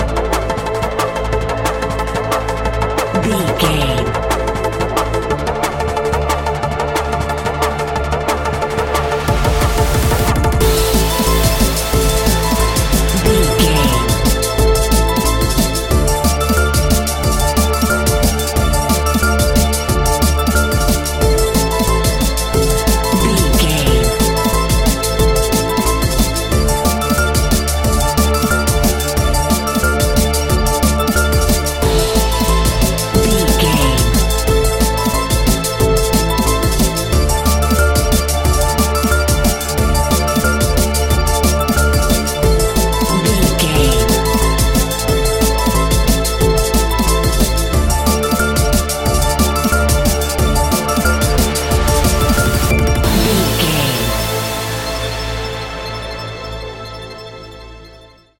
A great piece of royalty free music
In-crescendo
Aeolian/Minor
Fast
aggressive
powerful
dark
futuristic
industrial
frantic
epic
synthesiser
drum machine
electric piano
sub bass